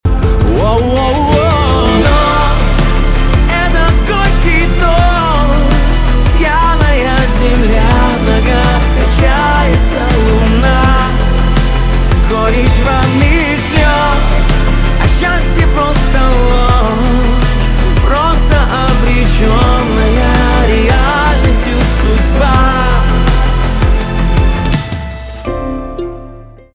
поп
грустные , печальные , барабаны , гитара
чувственные